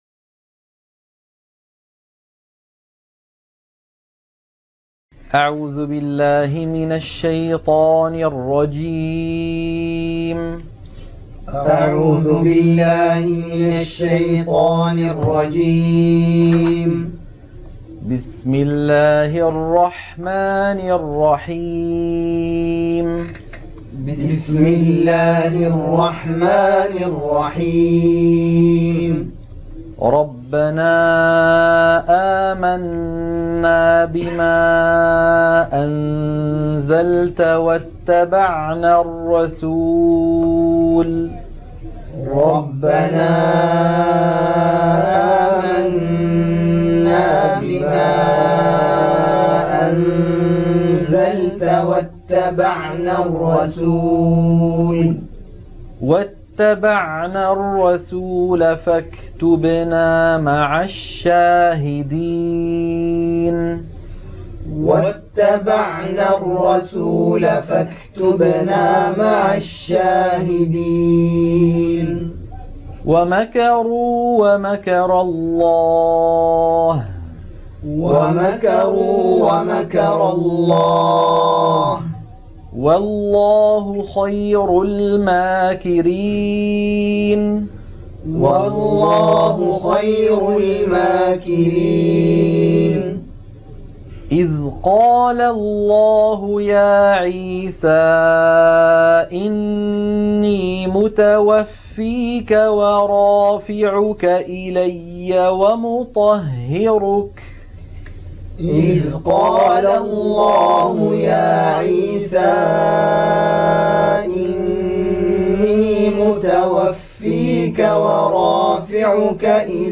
التلاوة المنهجية